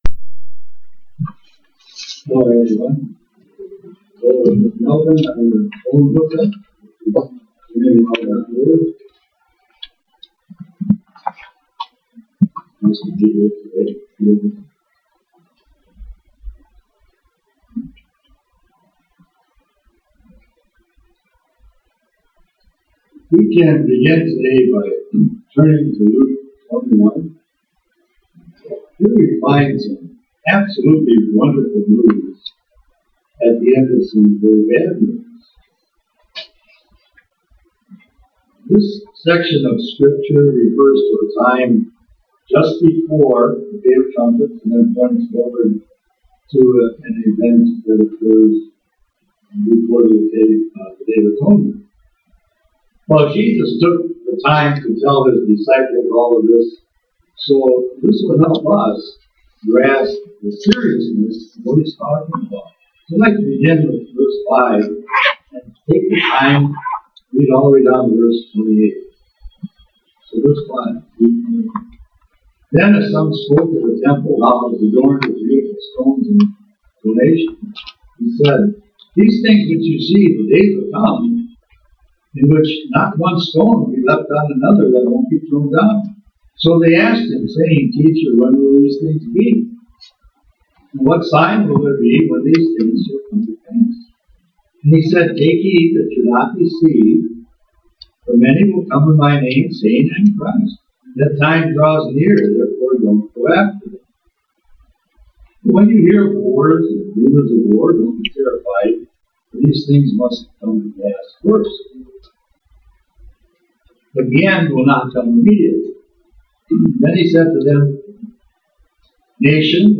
Sermons
Given in La Crosse, WI